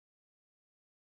Silence.mp3